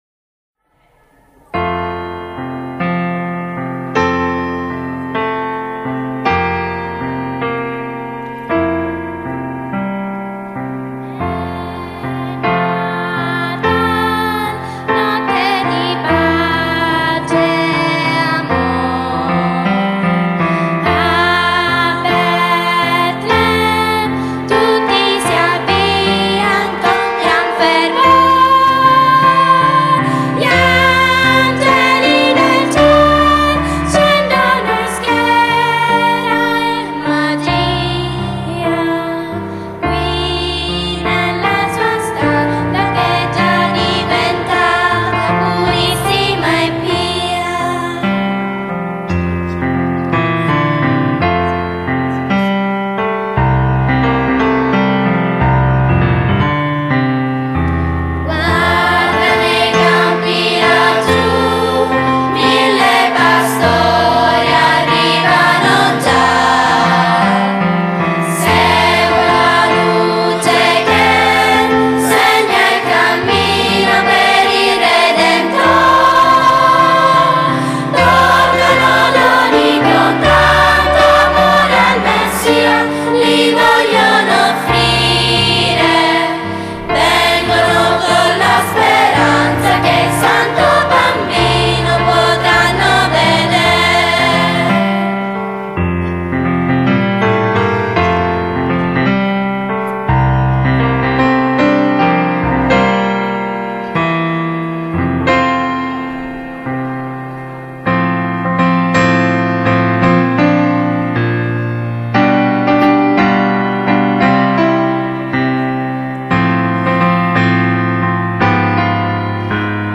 Organico voce solista, coro, pf. (od organo, ad libitum)
Esecuzione dal vivo (prima assoluta):
Luogo   Teatro Margherita
Coro polifonico (composto dagli alunni della scuola media F. Cordova)
Tonalitą definitiva: Fa magg.